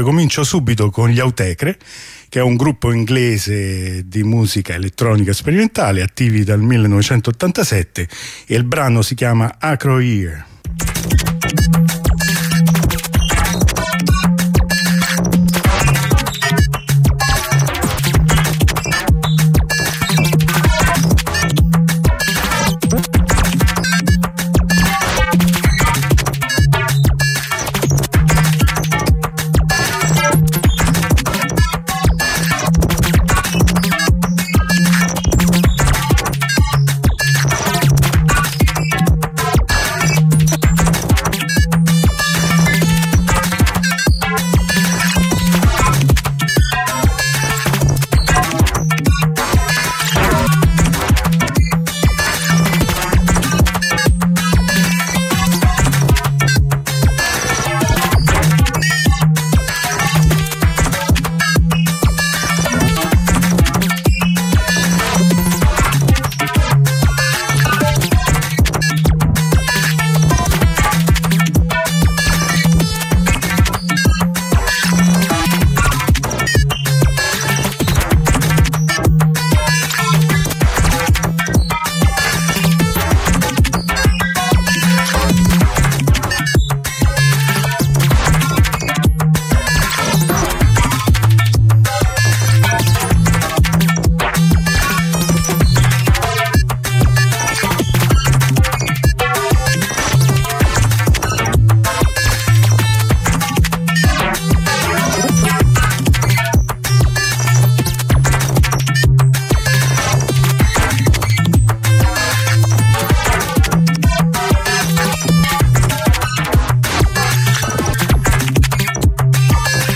Elettronica sperimentale OK.ogg